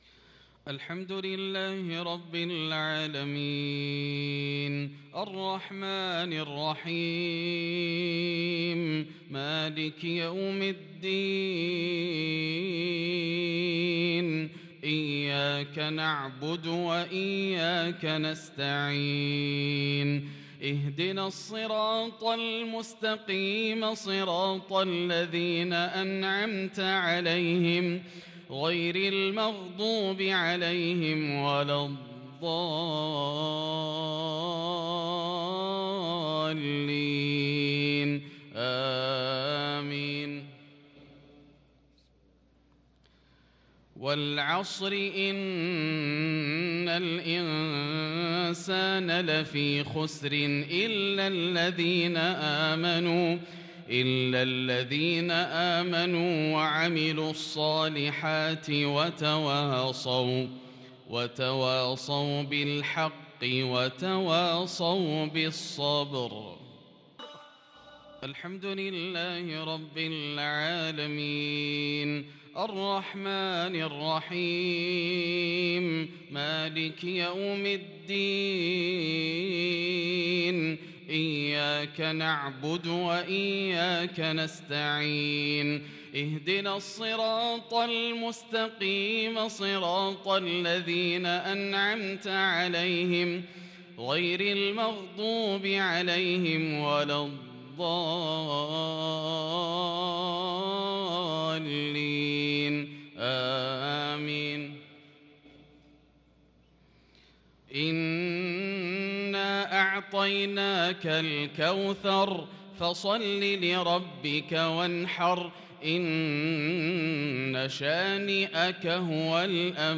صلاة المغرب للقارئ ياسر الدوسري 19 جمادي الأول 1443 هـ
تِلَاوَات الْحَرَمَيْن .